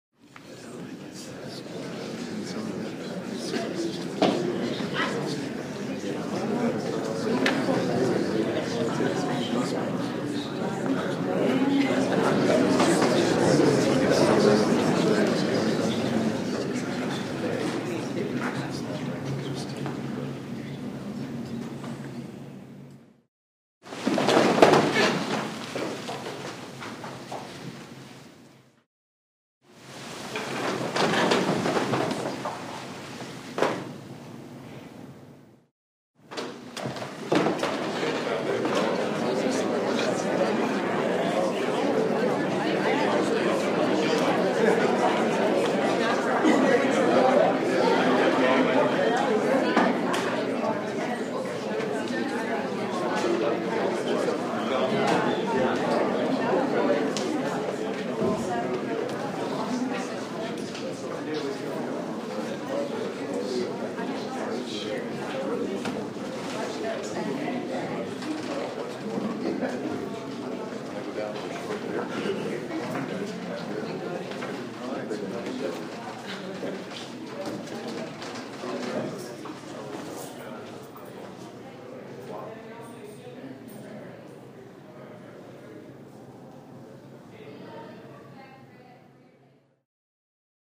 Звуки молотка судьи
На этой странице собраны звуки молотка судьи — от четких одиночных ударов до протяжных стуков.
Зал суда заполнен людьми, они встают и садятся